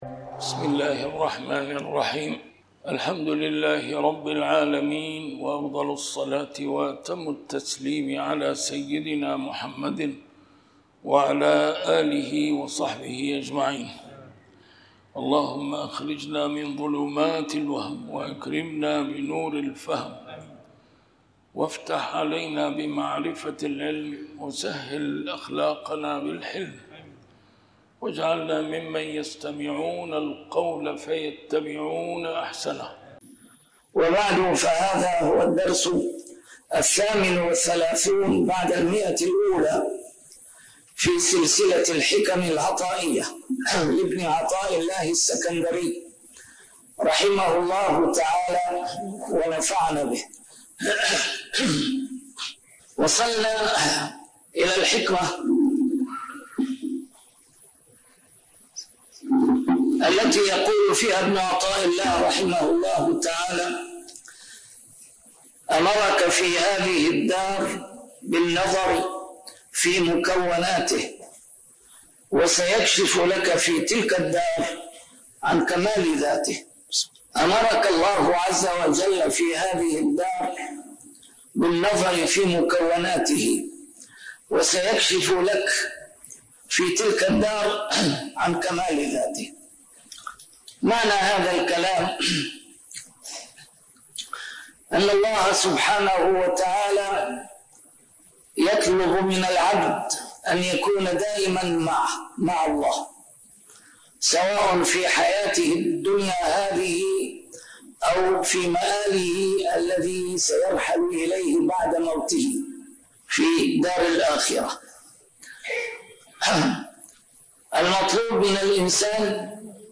A MARTYR SCHOLAR: IMAM MUHAMMAD SAEED RAMADAN AL-BOUTI - الدروس العلمية - شرح الحكم العطائية - الدرس رقم 138 شرح الحكمة 116